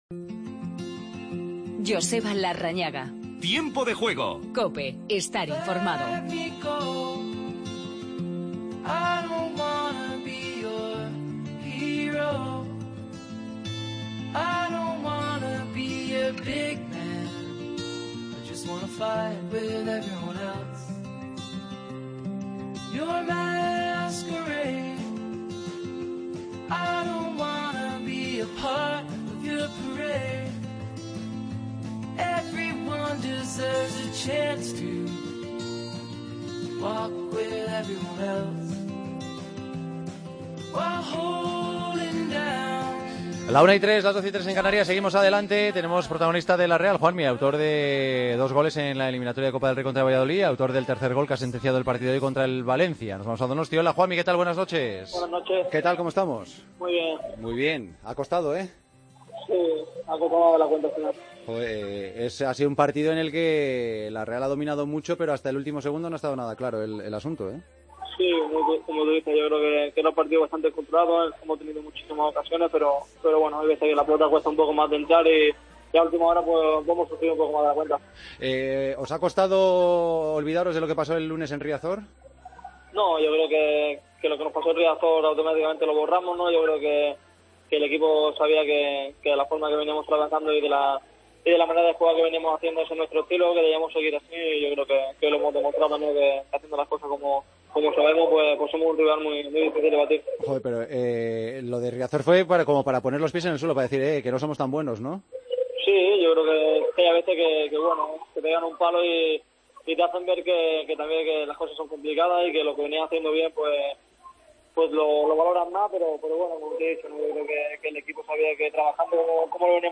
Hablamos con Juanmi de la victoria ante el Valencia. Repasamos lo ocurrido en Las Palmas - Leganés. Última hora de los encuentros de este domingo.